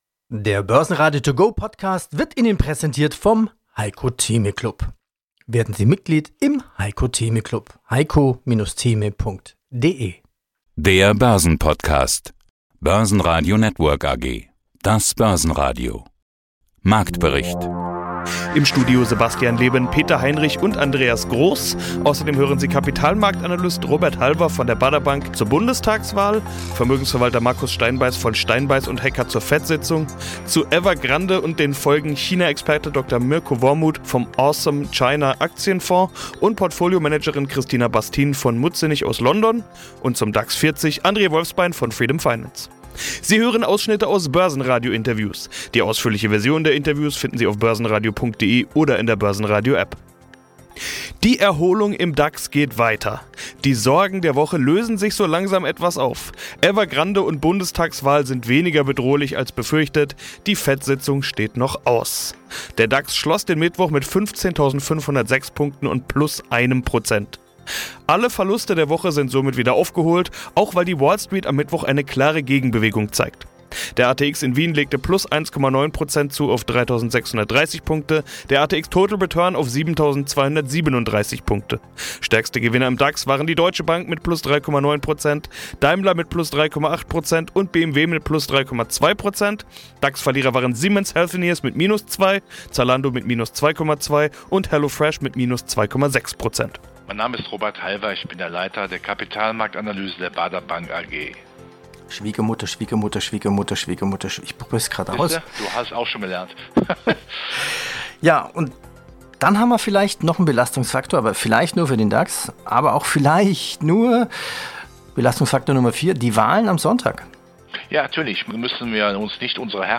Sie hören Ausschnitte aus Börsenradiointerviews.